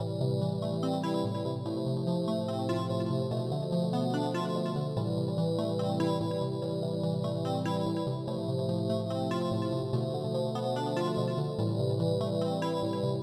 描述：一个小调酷的合成器，由我演奏的和弦。这个循环的风格是Lil Mosey x Lil Candy Paint。
Tag: 145 bpm Trap Loops Synth Loops 2.23 MB wav Key : A